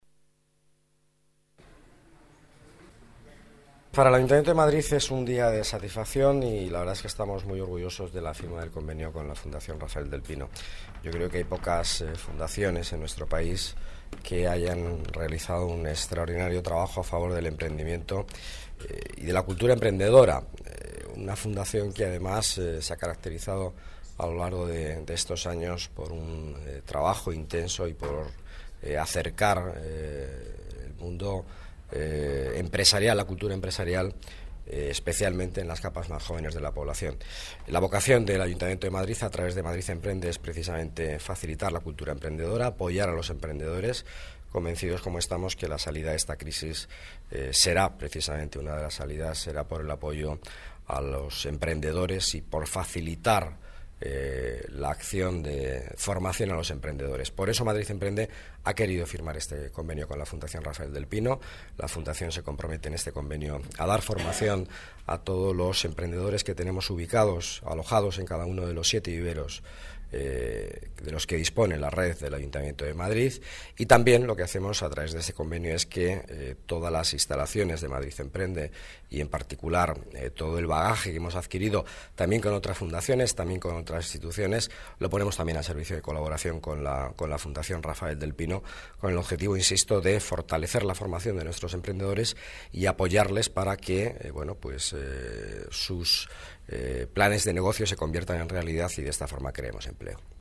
Nueva ventana:Declaraciones delegado de Economía y Empleo, Miguel Ángel Villanueva: convenio de colaboración con la Fundación Rafael del Pino